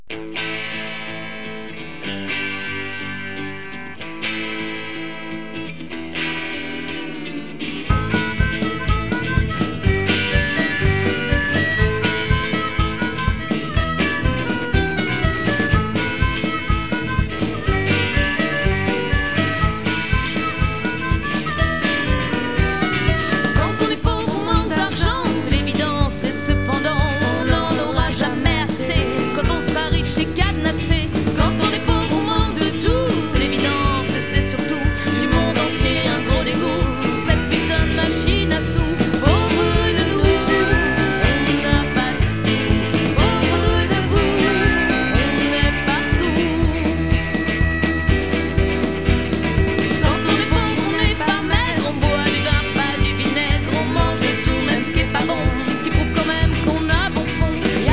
Musique Ethnique de la Basse Vallée de l'Ourcq